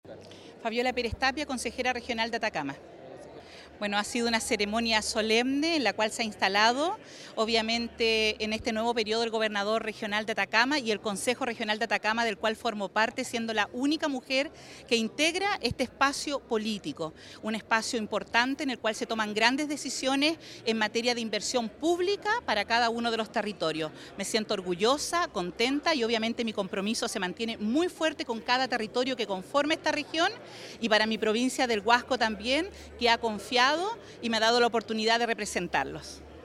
Durante la ceremonia, se entregaron reconocimientos a los consejeros salientes, quienes manifestaron su apoyo a los nuevos integrantes del Consejo Regional (CORE).
CONSEJERA-FABIOLA-PEREZ-.mp3